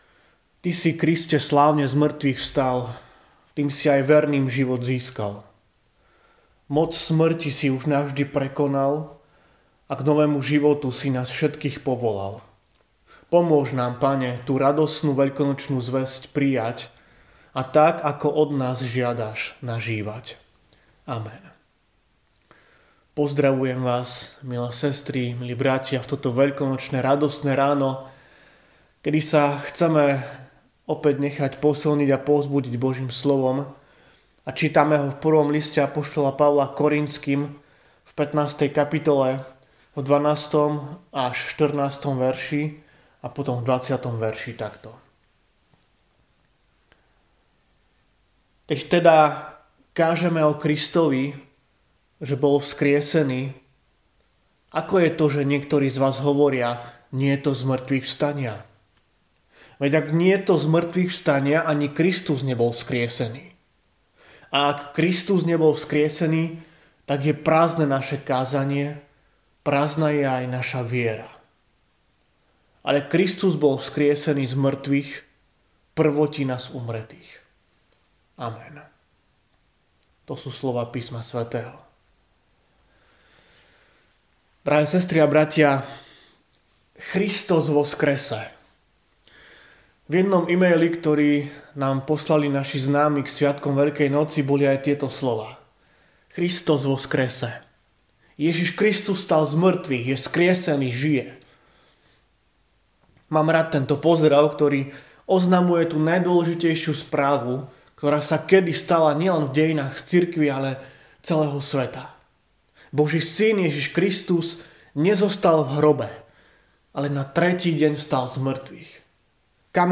Zvesť slova Božieho – 1. list apoštola Pavla Korintským 15, 12-14.20